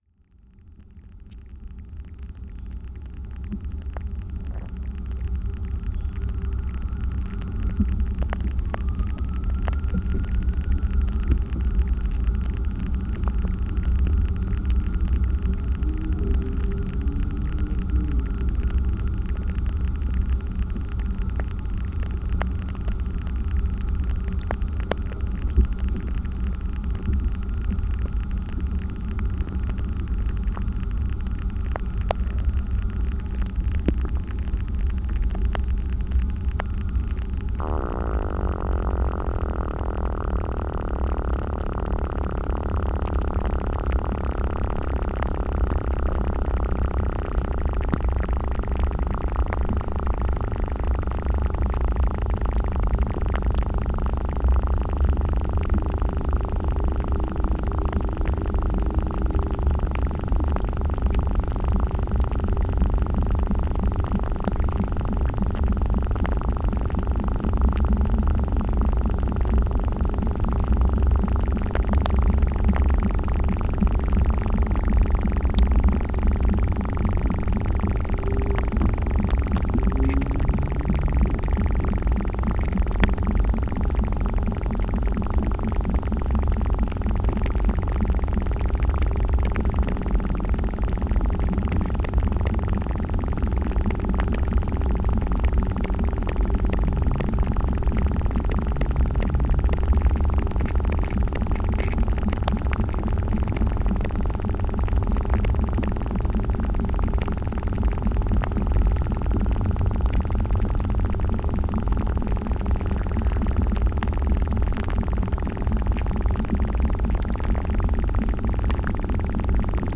Offering a hybrid mode of listening, the walk invites participants—equipped with headphones and audio receivers—to traverse the landscape while engaging with a layered soundscape composed of real-time environmental sounds and recordings made in the Arboretum using specially adapted techniques. These are interwoven with sonic material from a range of other forest environments—from the Pacific rainforests to Florida’s coastal hammocks—highlighting the rich and often overlooked acoustic lives of trees and their ecosystems.
From the percussive resonance of rain on palm fronds and the staccato clicks of water seeping into the roots of a Siberian elm, to the barely perceptible rustles of insect larvae, the soundwalk reveals hidden acoustic dimensions of trees and their inhabitants.
Arnold Arboretum of Harvard University, Boston